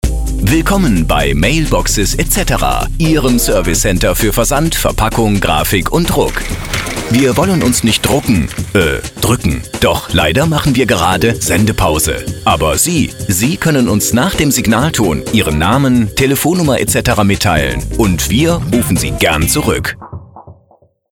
Anrufbeantworter außerhalb der Öffnungszeiten
Mail-Boxes-Etc.-AB-ausserhalb-der-Geschaeftszeiten.mp3